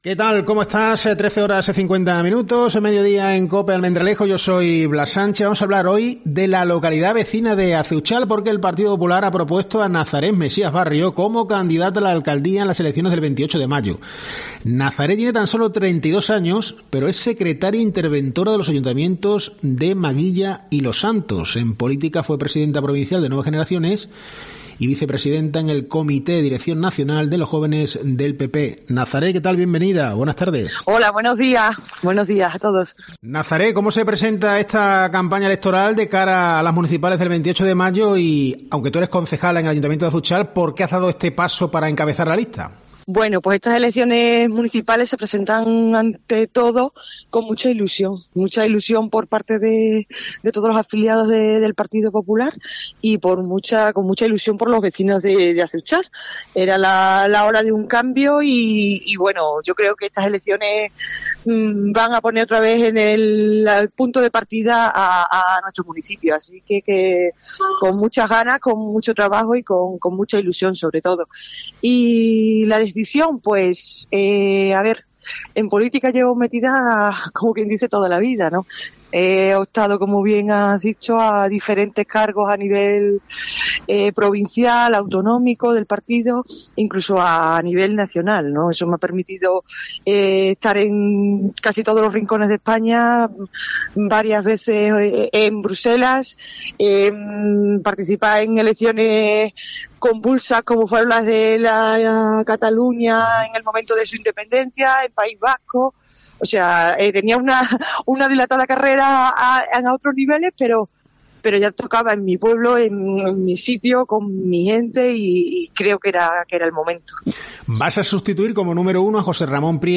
En COPE, hemos hablado con ella.